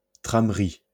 [tʁamʁi](info) ìsch a frànzeescha G’mainda mìt 154 Iiwoohner (Schtànd: 1.